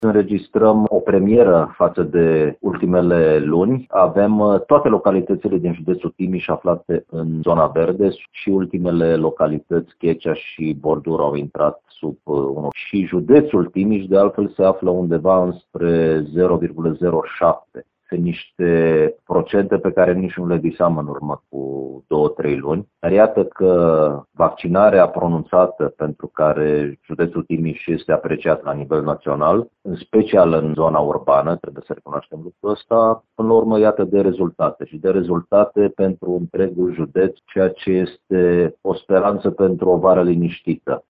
Ultimele două comune din județ, în care era o rată de infectare de peste unu la mia de locuitori, au coborât sub această cifră,  spune subprefectul Ovidiu Drăgănescu.